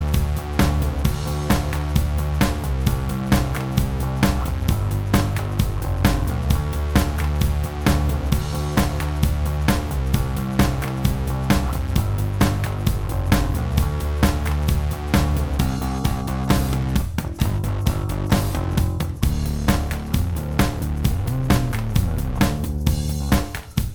Minus All Guitars Rock 4:04 Buy £1.50